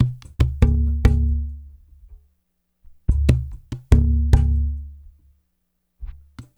BOL WD DRY.wav